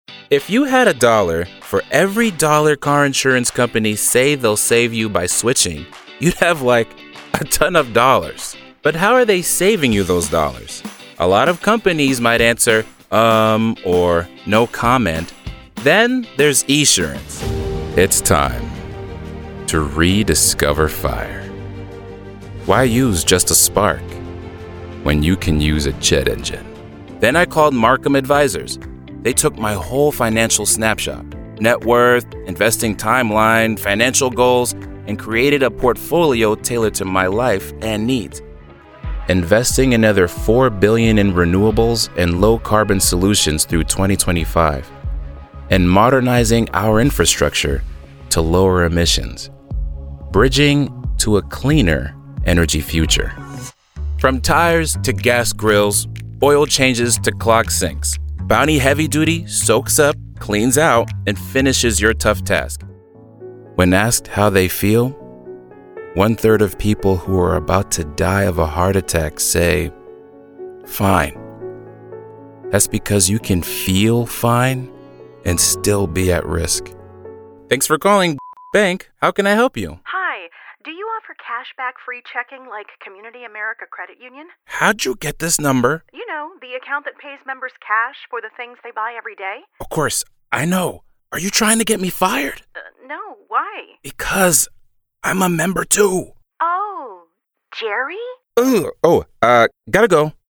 Commercial Demo
English - United Kingdom, English - British and European